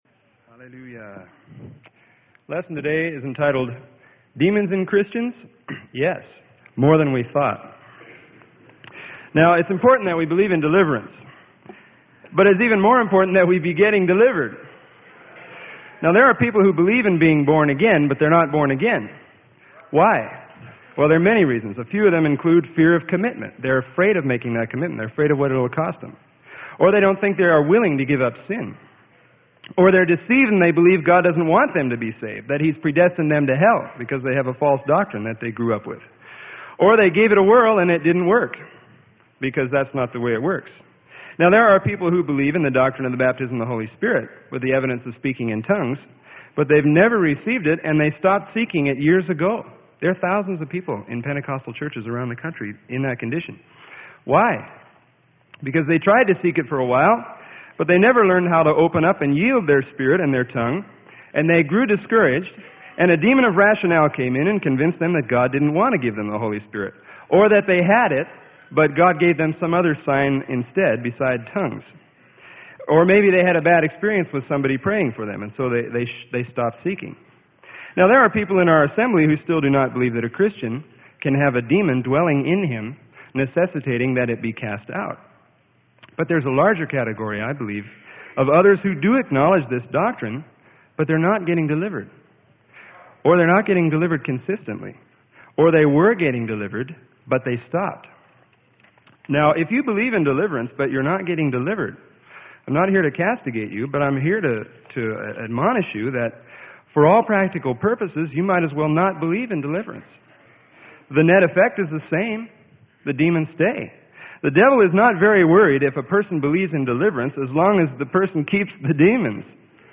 Sermon: DEMONS IN CHRISTIANS?